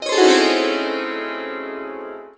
SITAR LINE12.wav